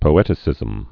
(pō-ĕtĭ-sĭzəm)